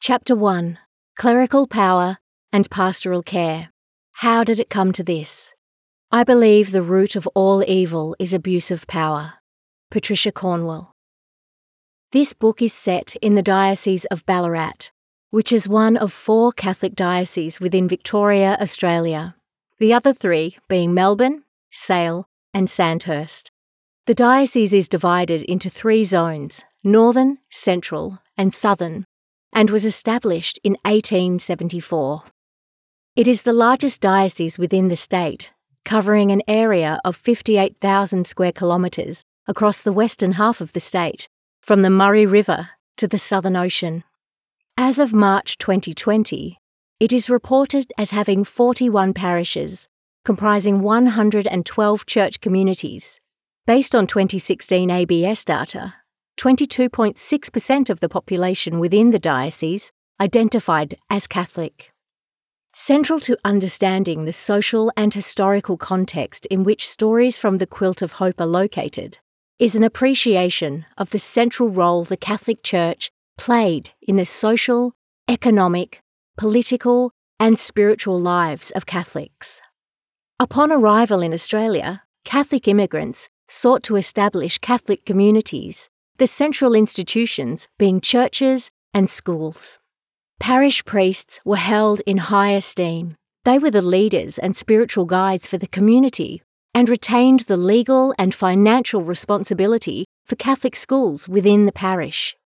Female
A bright, intelligent and natural voice with the ability to interpret nuance and complex characters.
Audiobooks
Australian Narration Example
Words that describe my voice are bright, Natural, sincere.
0922Narration_Demo_.mp3